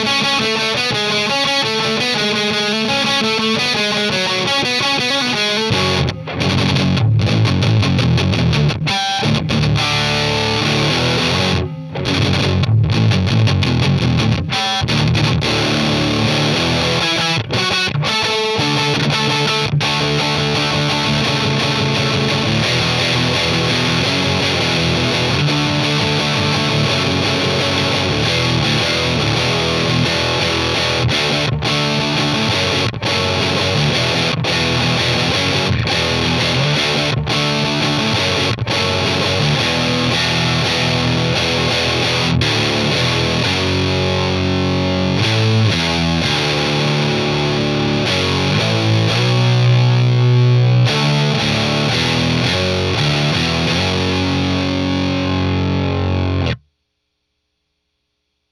Dynamic Mic on CAB comparison.
A shoot-out between six dynamics on a 2×12 fitted with greenbacks.